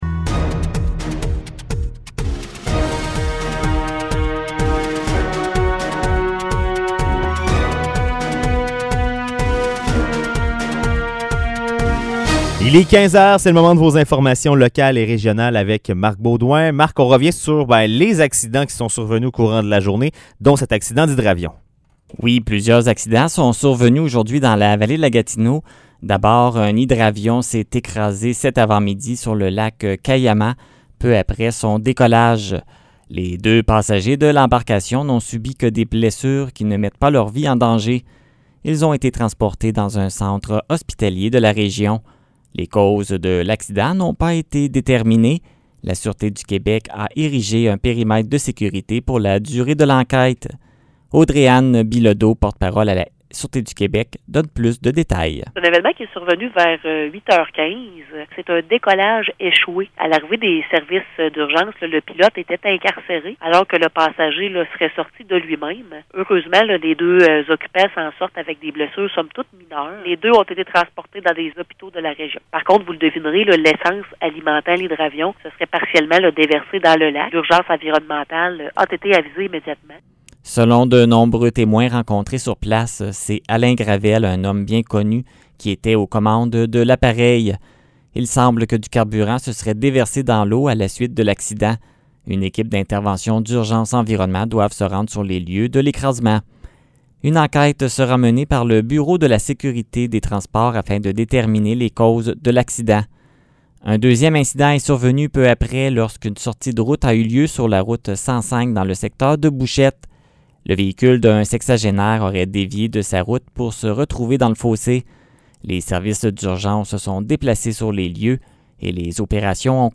Nouvelles locales - 2 juillet 2021 - 15 h